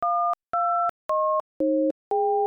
You Want That Dial Tone, Don't You? (0:02)
Close Encounters Dial Tones.mp3